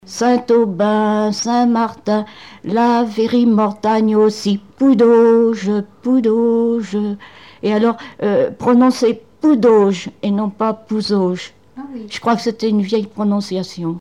Genre brève
Témoignages et chansons
Pièce musicale inédite